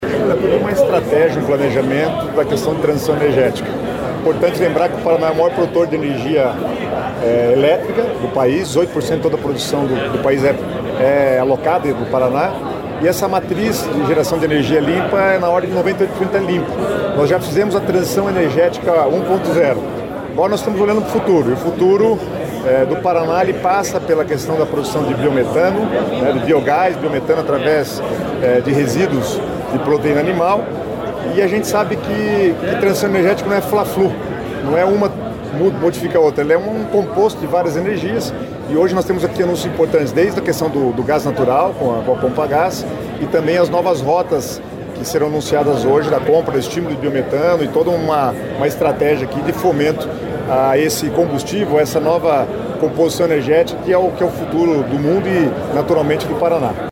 Dentre as iniciativas, está a expansão do atendimento do fornecimento de gás biometano nas cidades de Londrina e Maringá, com um investimento de R$ 100 milhões. O secretário de estado de Planejamento, Guto Silva, falou sobre o projeto.